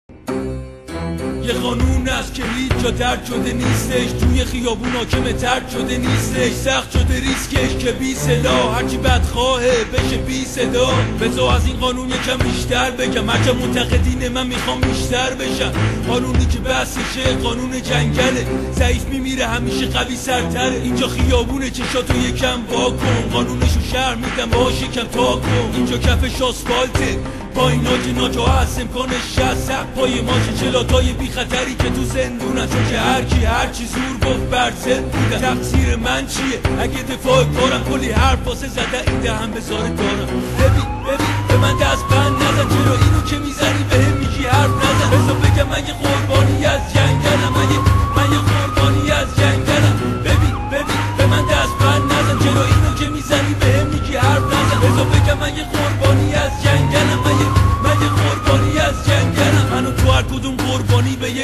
دف
ساز ايراني در سبك خارجي